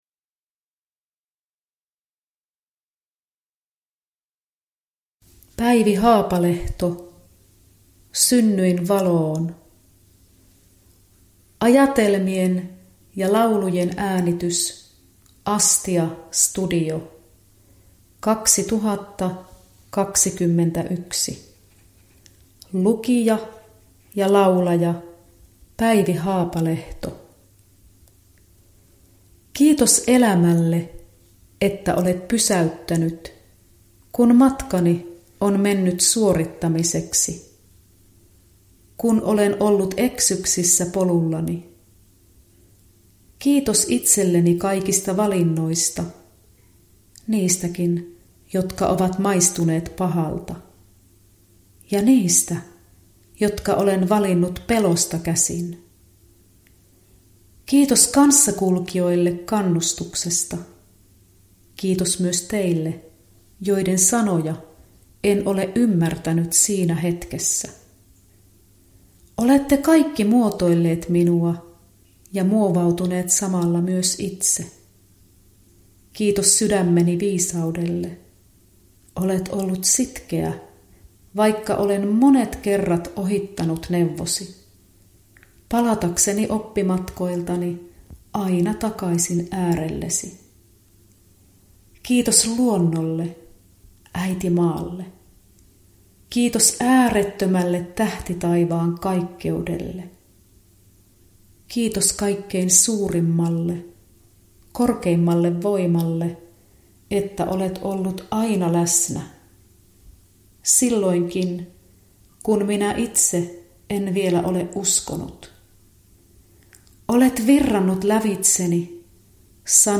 Synnyin valoon – Ljudbok – Laddas ner
Rauhallisella temmolla etenevää, elämän tunteiden vuoropuhelua, pelosta rakkauteen, pimeästä valoon.
Äänite sisältää 11 ajatelmaa ja 10 laulua